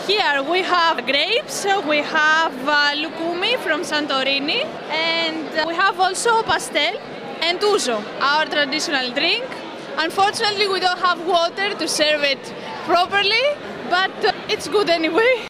Mówią uczestnicy Forum